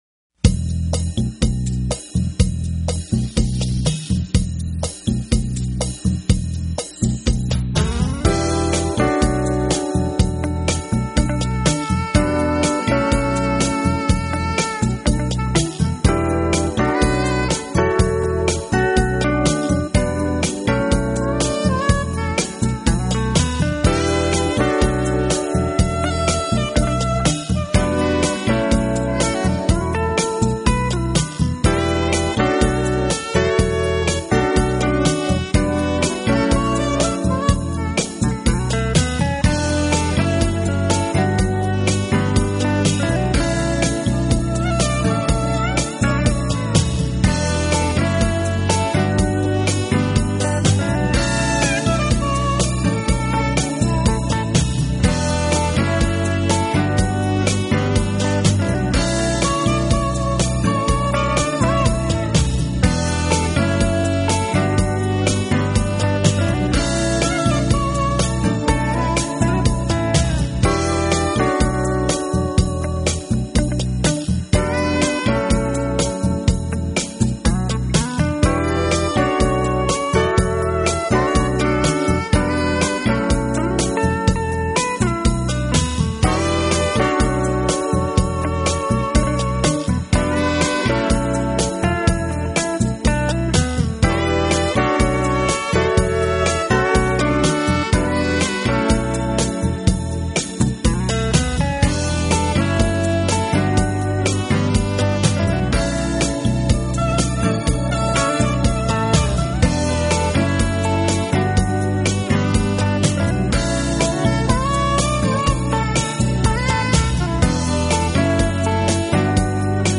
Genre: Rock
Styles: Contemporary Jazz, Crossover Jazz, Jazz-Rock